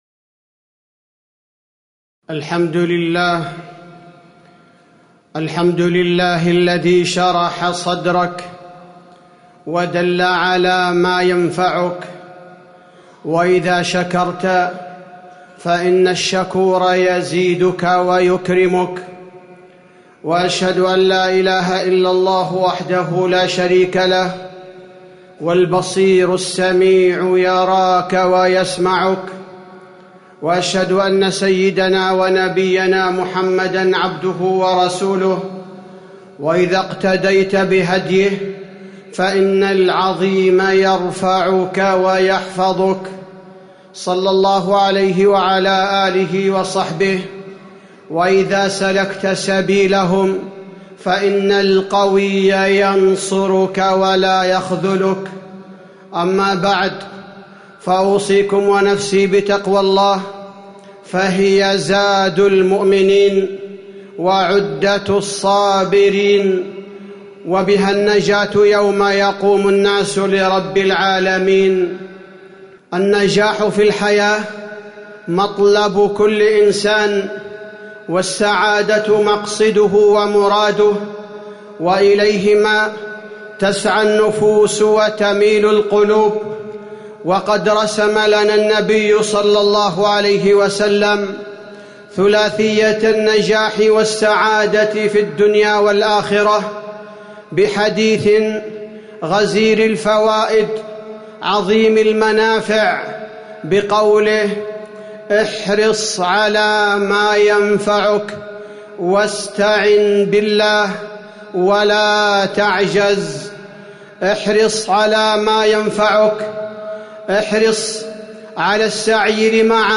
تاريخ النشر ٥ جمادى الأولى ١٤٤٠ هـ المكان: المسجد النبوي الشيخ: فضيلة الشيخ عبدالباري الثبيتي فضيلة الشيخ عبدالباري الثبيتي احرص على ماينفعك